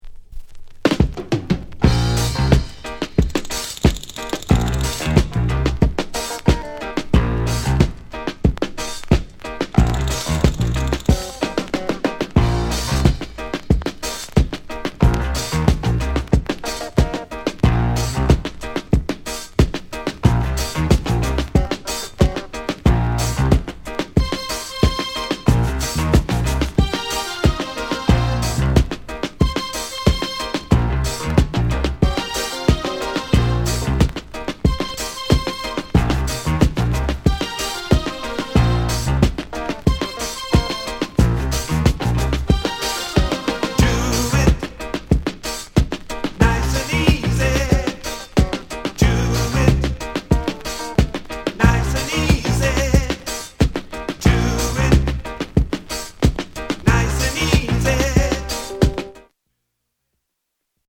FUNKY REGGAE